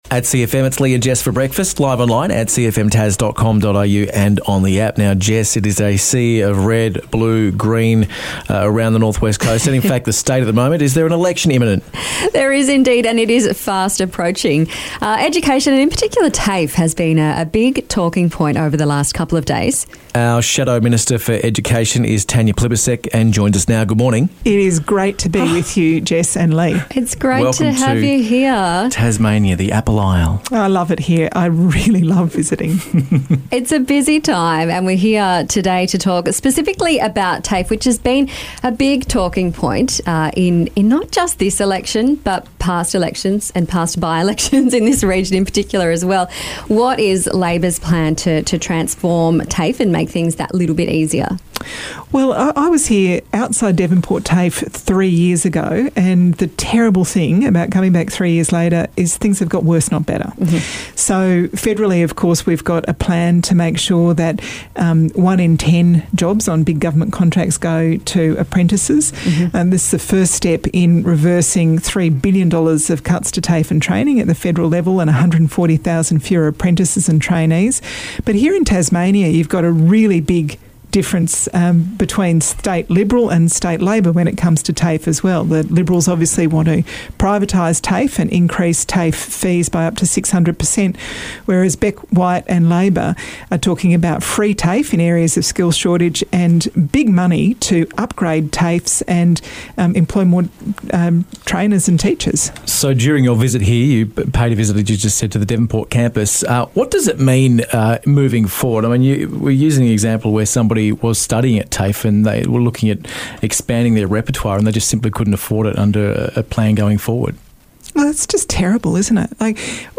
Shadow Education Minister, Tanya Plibersek, stopped by the studio